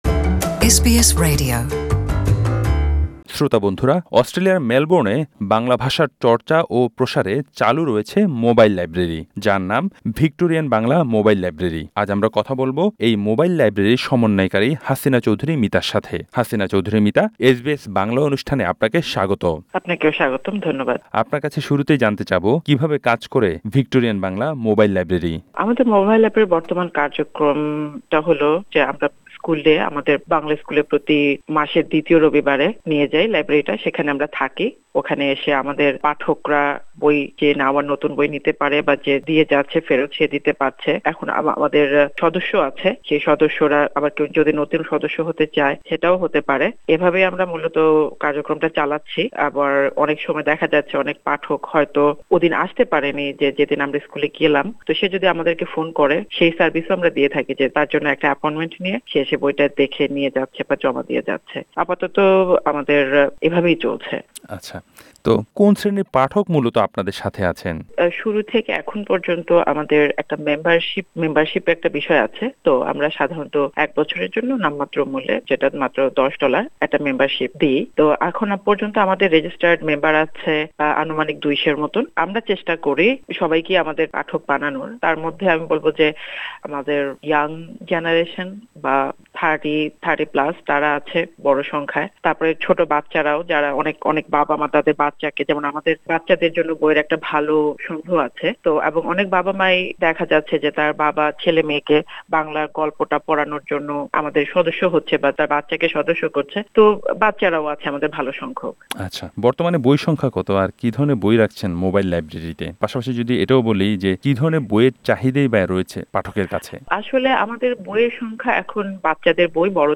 বাংলা চর্চা ও প্রসারে গত তিন বছর ধরে মেলবোর্ন প্রবাসী বাংলাদেশীদের কাছে ছুটে চলেছে ভিক্টোরিয়ান বাংলা মোবাইল লাইব্রেরি। এসবিএস বাংলার সাথে ফোনালাপে কথা বলেছেন